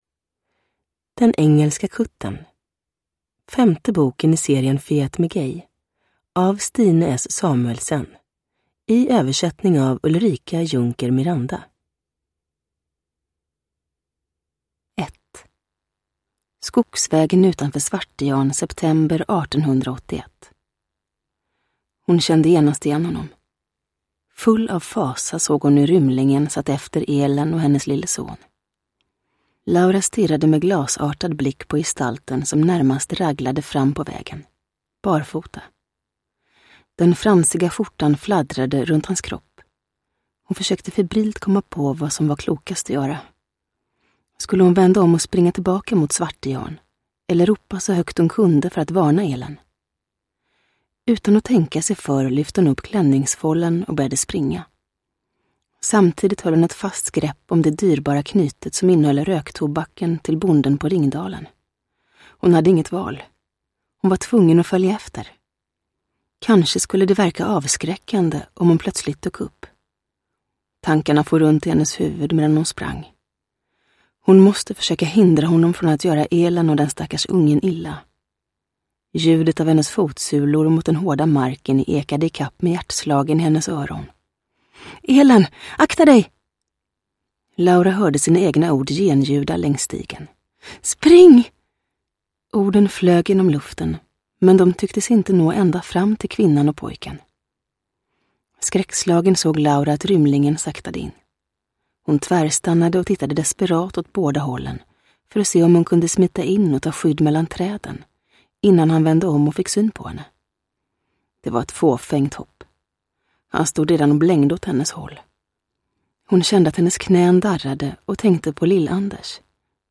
Den engelska kuttern – Ljudbok – Laddas ner
Uppläsare: Julia Dufvenius